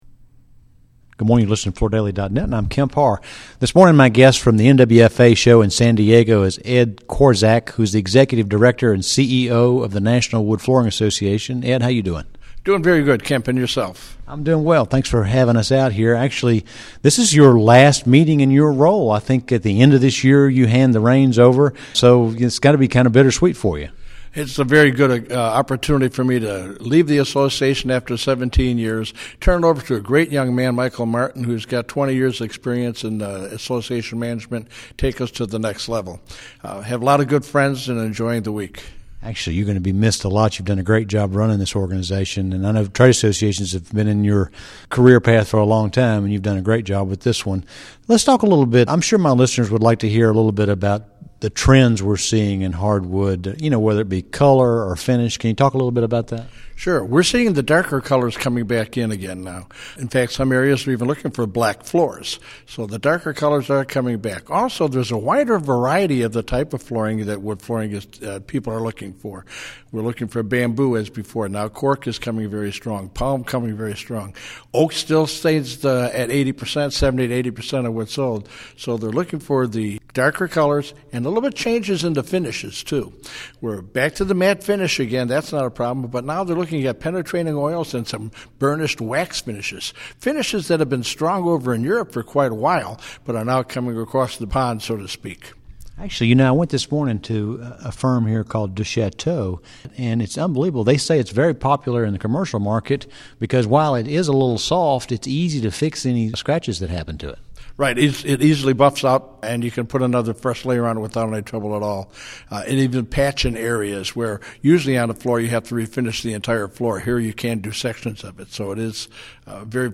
Listen to the interview to hear details about product trends, sales volumes